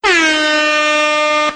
Airhorn.wav